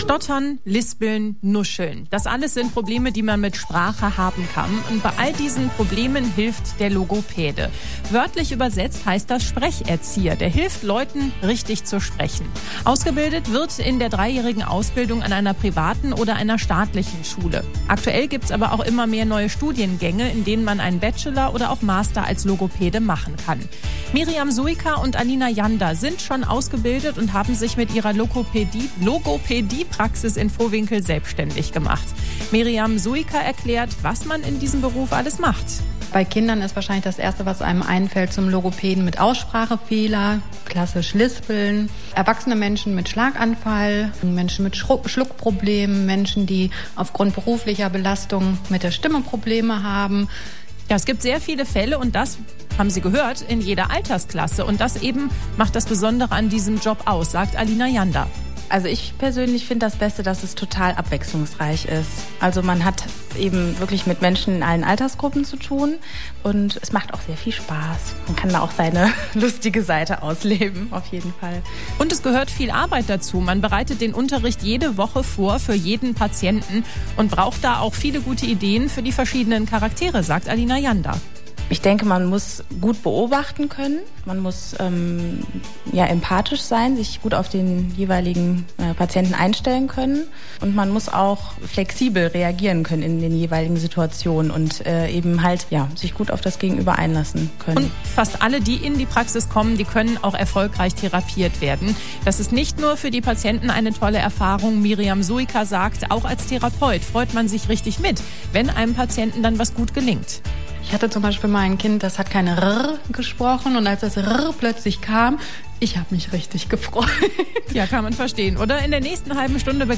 Im Radio